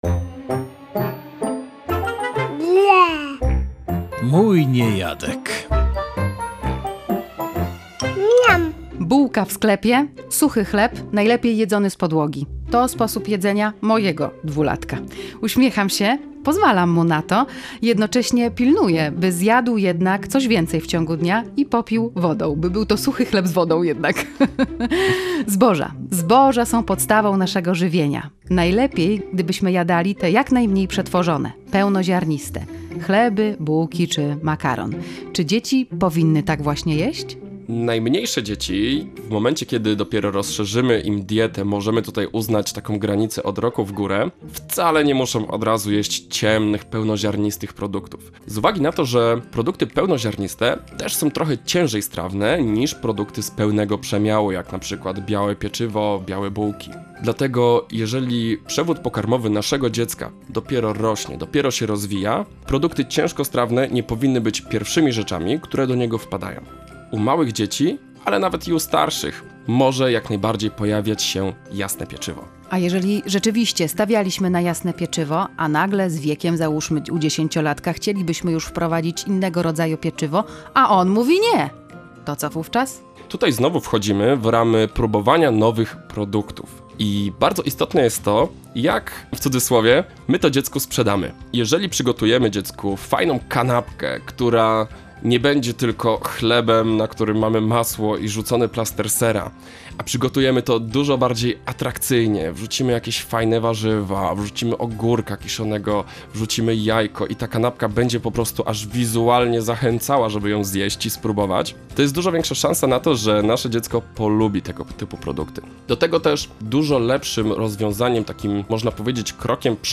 dietetyk pediatryczny